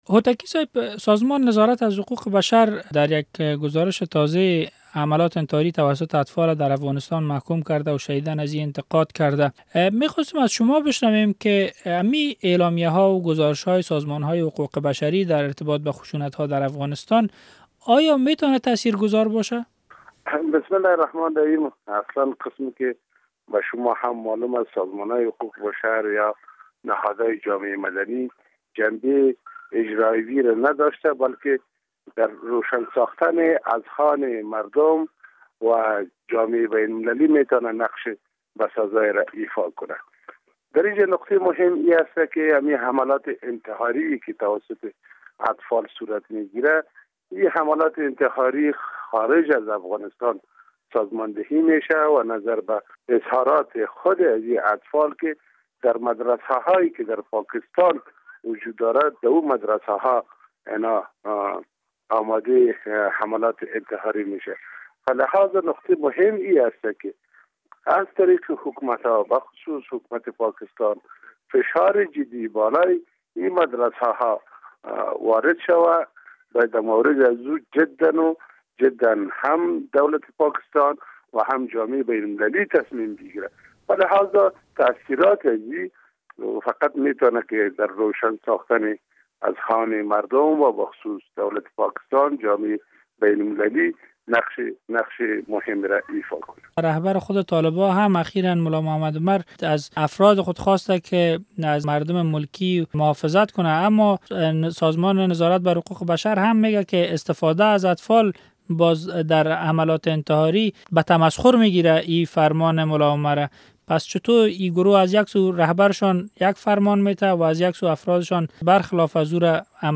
مصاحبه درمورد استفاده از کودکان درحملات انتحاری در افغانستان